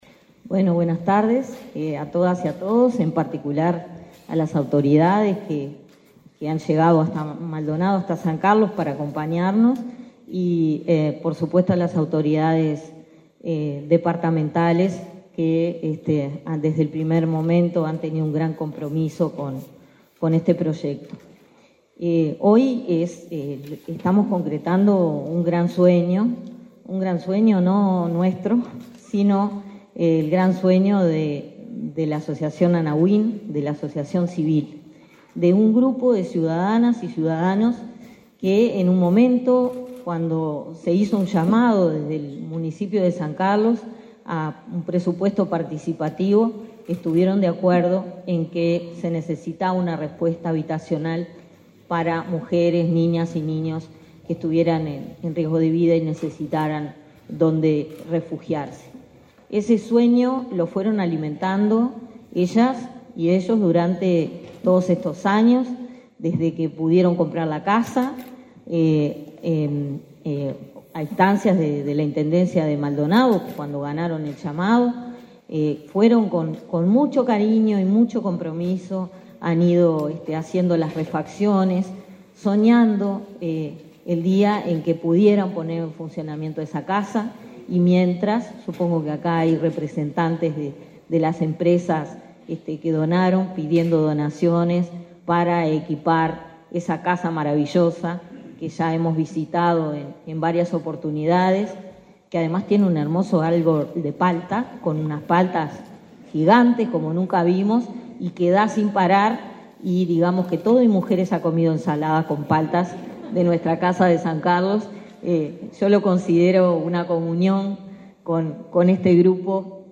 Conferencia de prensa por la inauguración de centro de estadía en San Carlos, Maldonado
Participaron en el evento el ministro de Desarrollo Social, Martín Lema, y la directora del Inmujeres, Mónica Bottero.